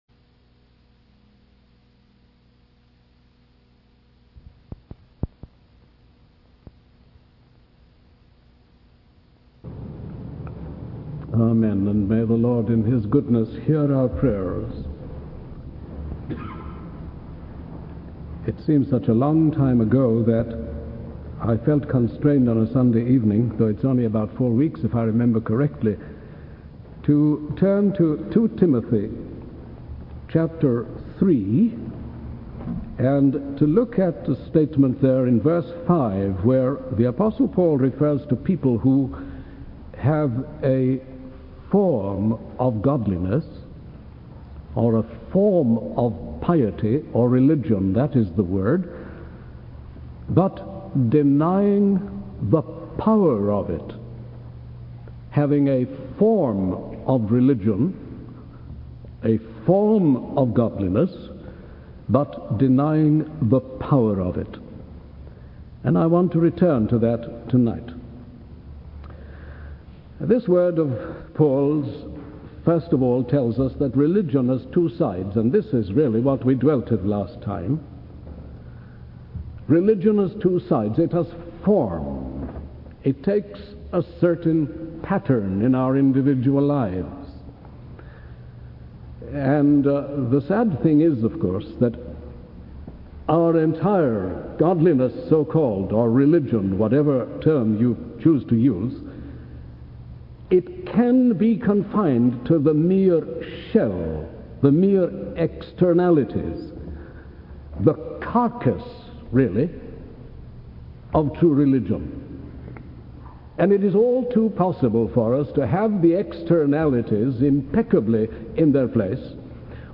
In this sermon, the speaker emphasizes the importance of true religion and the power it holds. He discusses the three ways in which the power of true religion manifests itself: the power of God over us, the power of God in us, and the power of God through us. The speaker urges believers to not only believe in God but to also experience the power of God in their lives and exemplify it to the world.